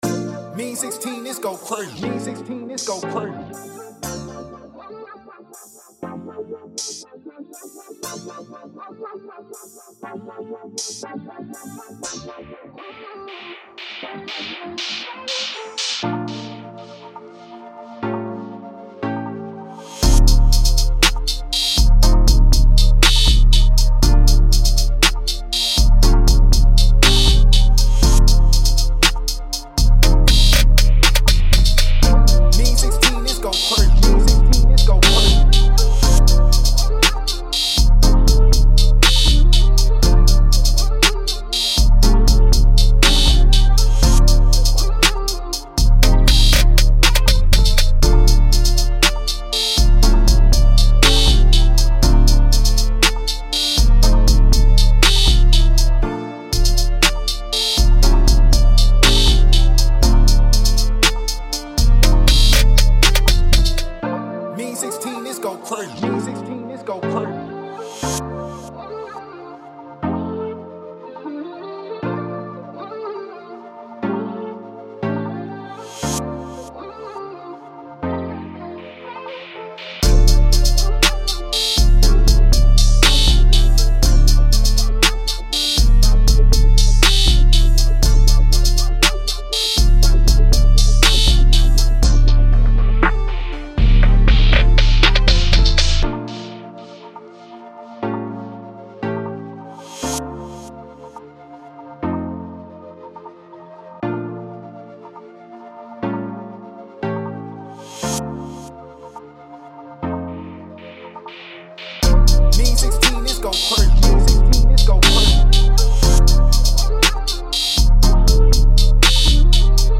rnb beats